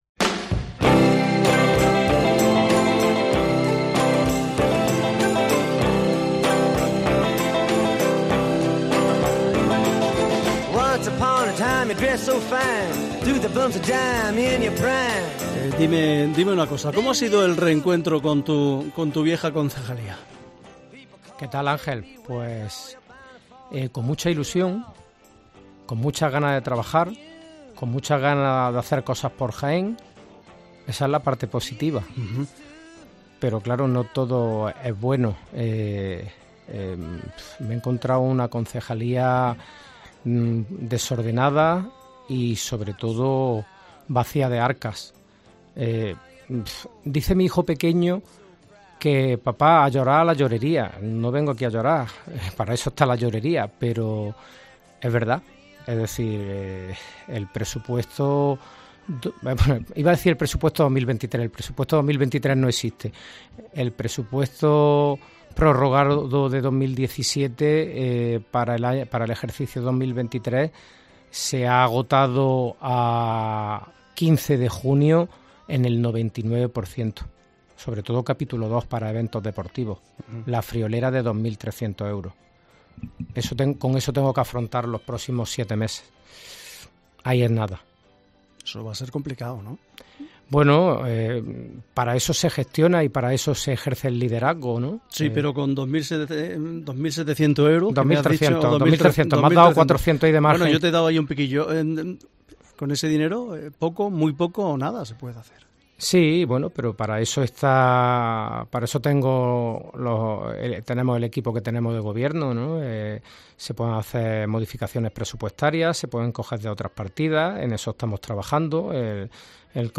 Charlamos con Chema Álvarez, concejal de deportes del Ayuntamiento de Jaén